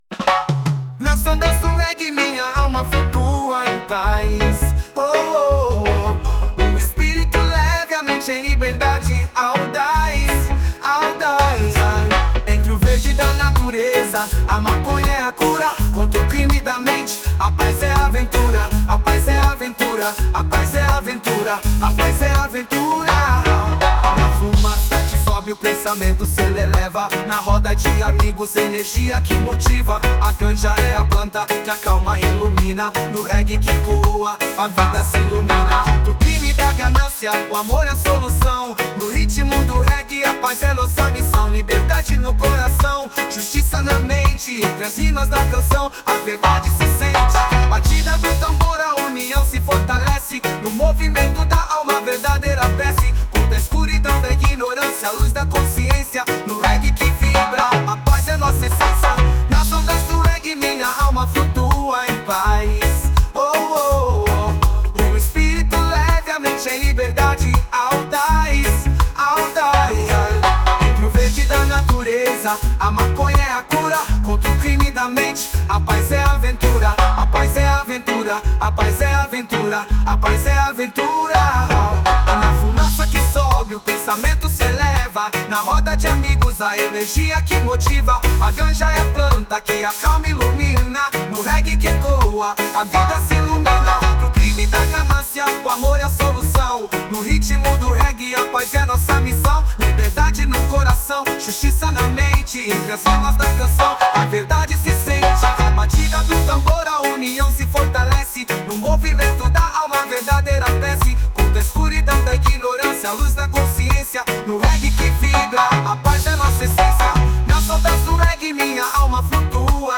2024-07-22 12:36:34 Gênero: Reggae Views